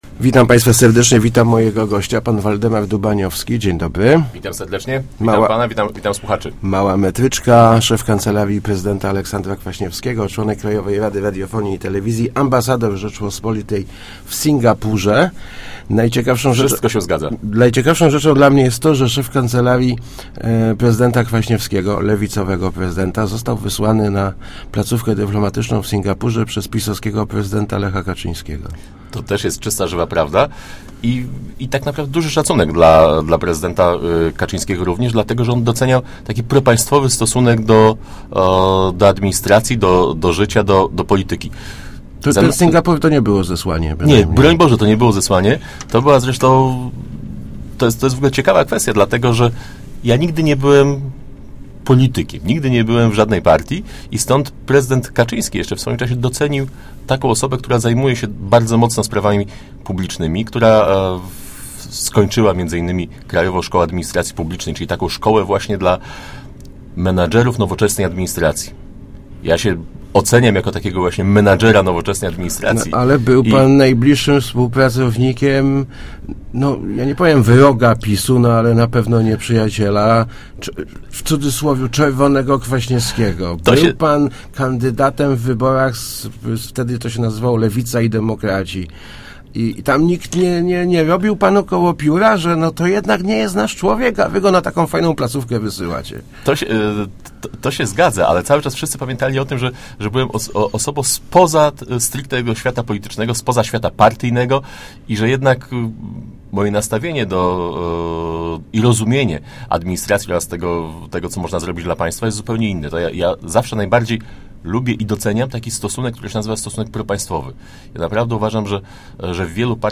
Leszno nie b�dzie oczywi�cie Singapurem, ale ma kilka atutów, które mog� da� mu impuls do rozwoju - mówi� w Rozmowach Elki Waldemar Dubaniowski, by�y szef Kancelarii Aleksandra Kwa�niewskiego i ambasador RP w Singapurze. Jak podkre�li� jednym z kluczy do sukcesu "azjatyckiego tygrysa" by�a edukacja.